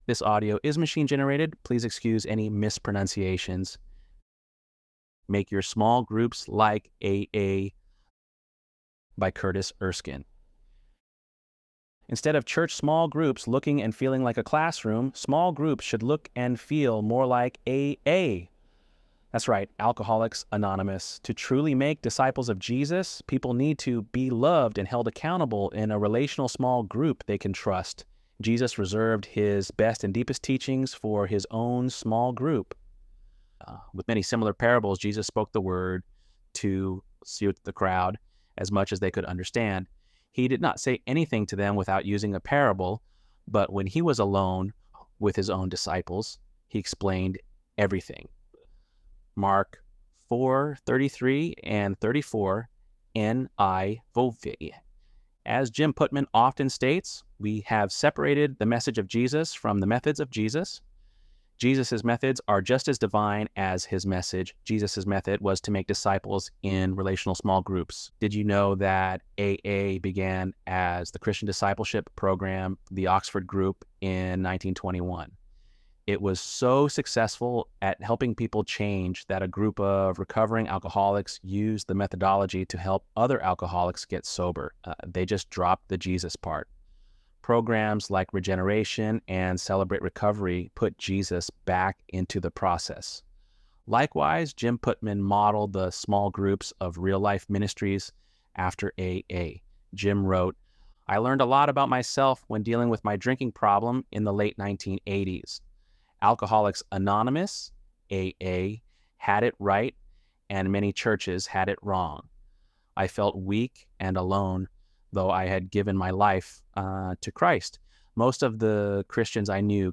ElevenLabs_10.9.mp3